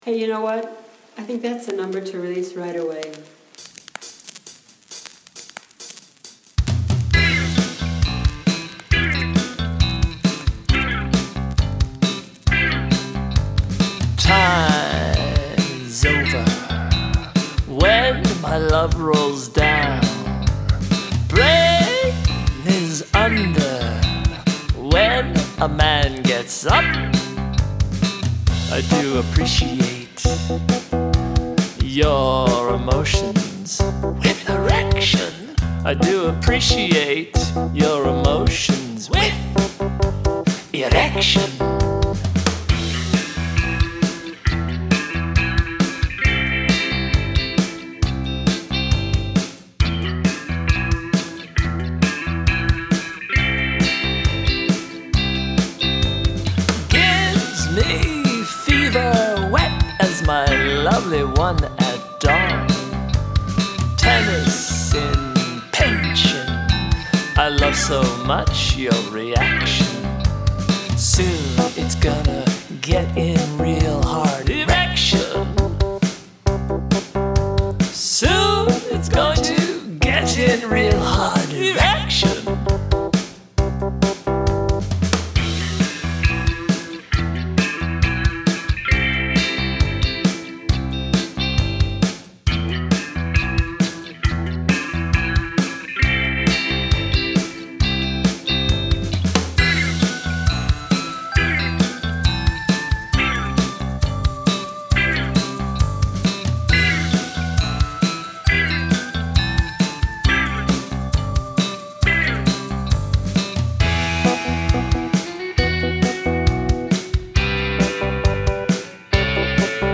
It's a cover of an old song from the early 80's